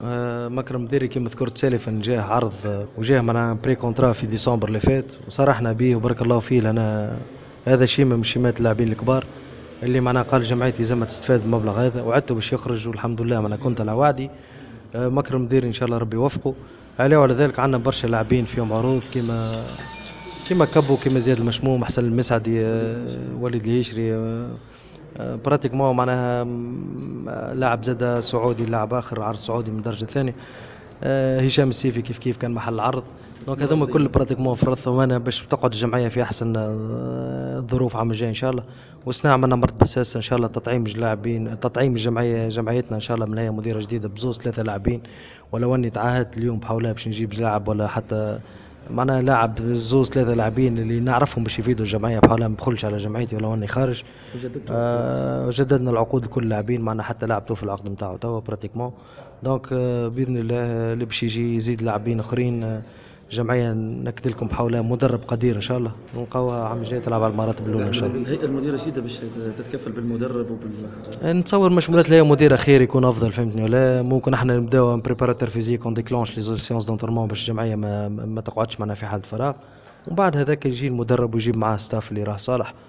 عقدت الهيئة المديرة للإتحاد المنستيري ندوة صحفية لتسليط الضوء حول الوضعية المالية للفريق قبل إنعقاد الجلسة الإنتخابية القادمة بعد إعلان الهيئة الحالية عدم المواصلة لفترة نيابية قادمة.